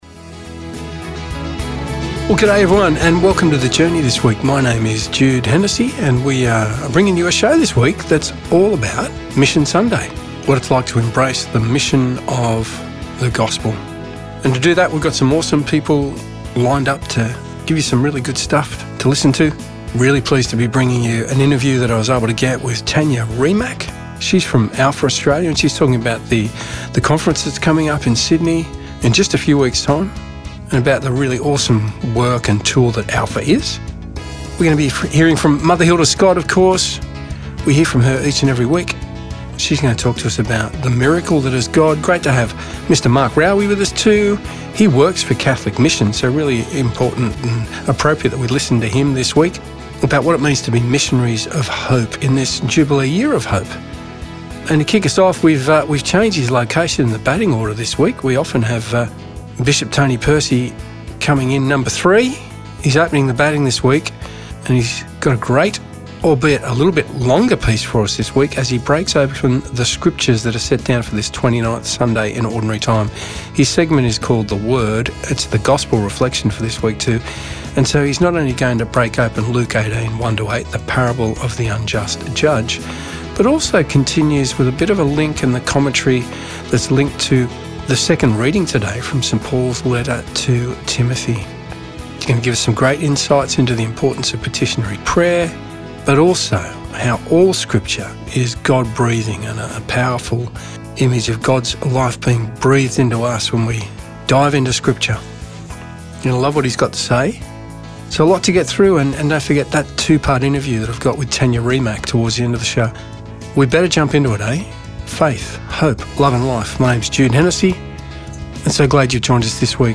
"The Journey" is a weekly one hour radio show produced by the Catholic Diocese of Wollongong and aired on various Christian Radio Stations around Australia
Each week, there is a reflection on the Sunday Gospel reading. Add to that some great music and interviews with people doing amazing things right around the globe and you’ve got a show that is all about faith, hope love and life.